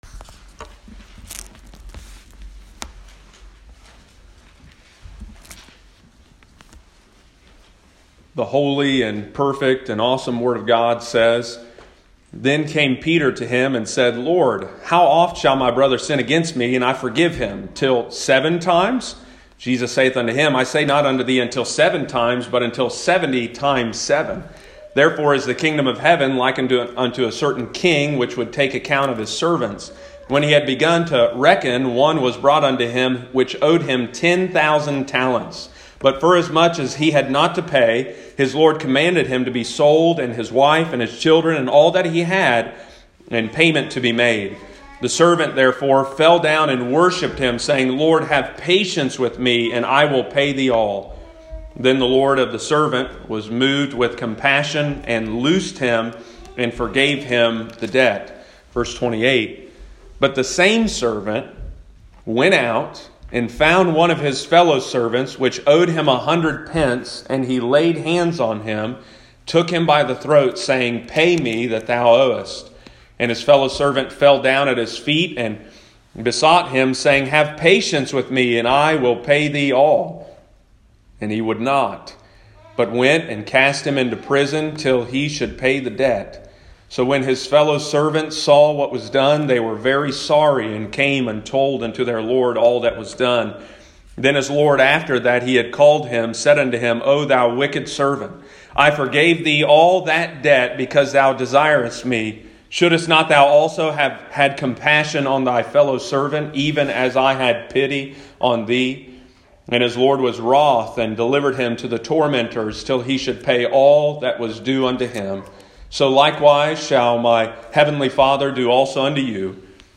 Sunday morning, November 22, 2020.